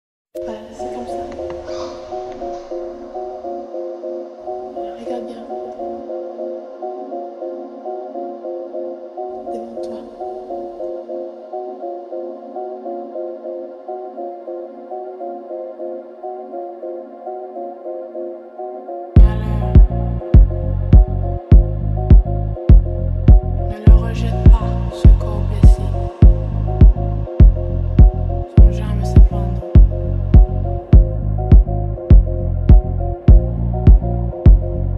Модульные синтезаторы и глитчи трека
создают футуристичный саунд
Жанр: Электроника